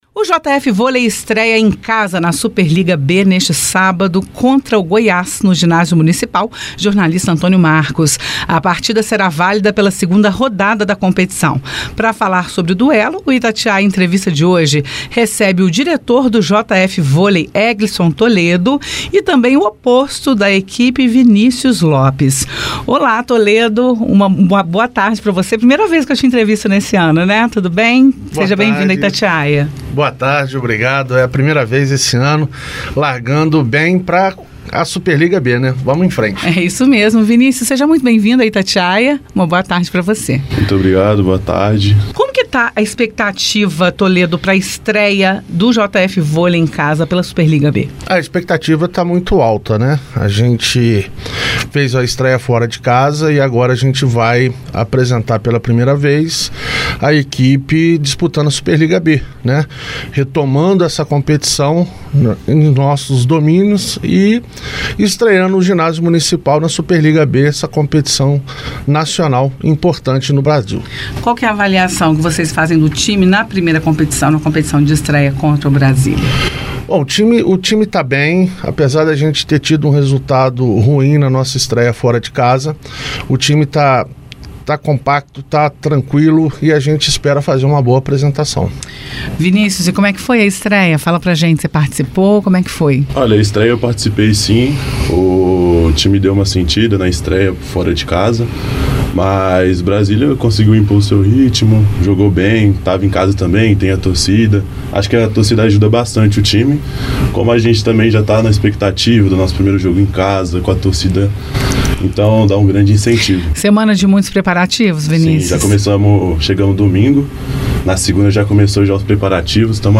Itatiaia-Entrevista-JF-Volei.mp3